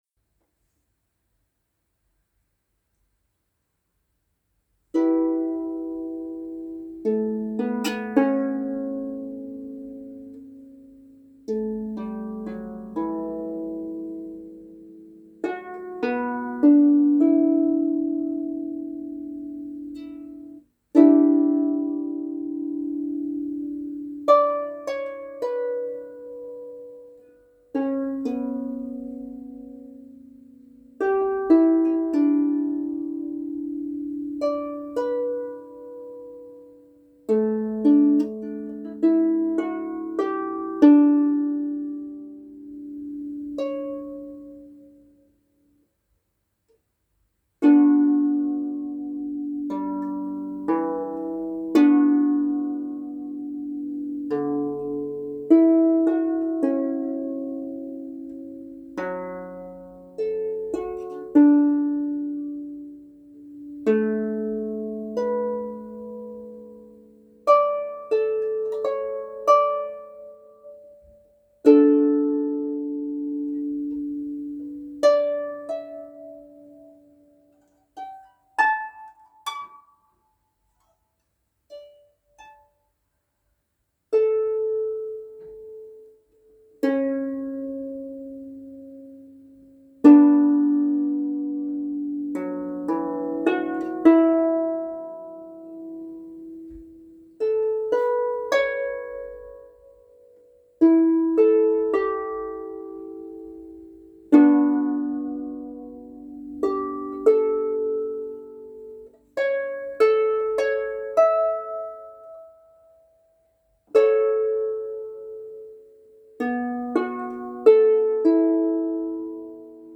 Main Page Works Encrypted music (2023) Two musical pieces that booth hold encrypted messages based on morse code... but not on the length of the tones.